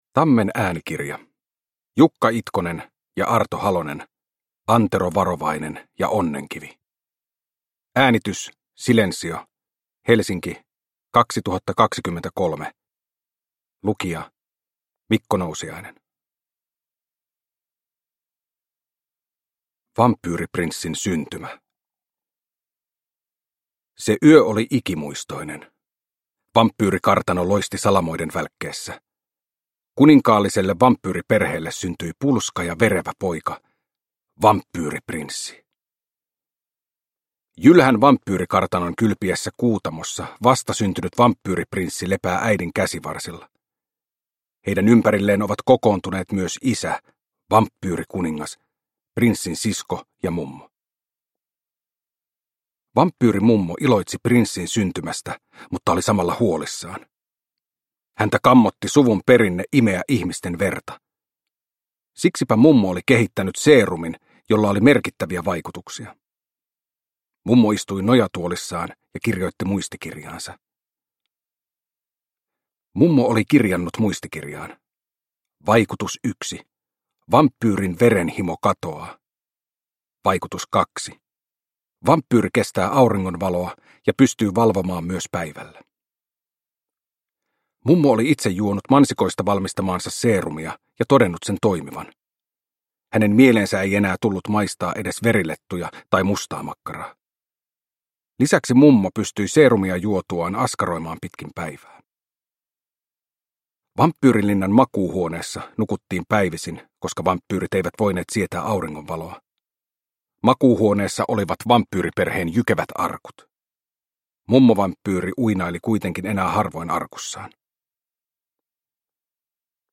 Uppläsare: Mikko Nousiainen